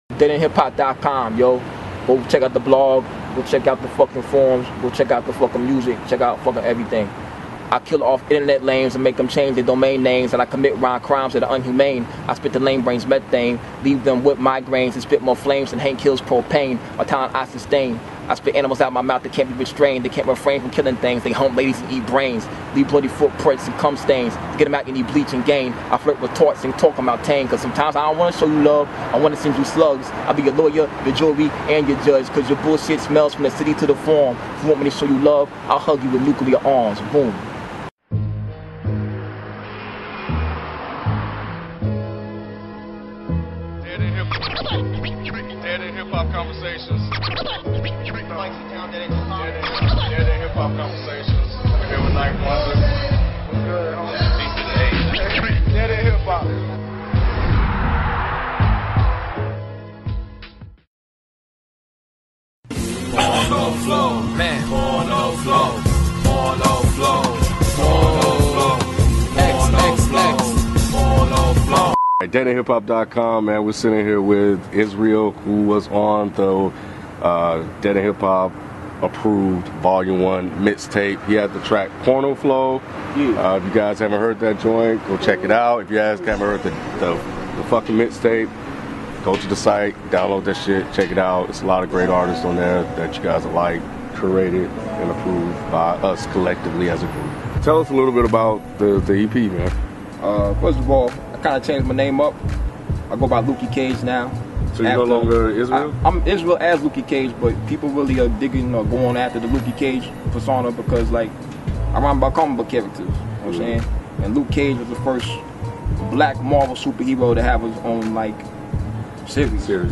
DEHH Indie Interviews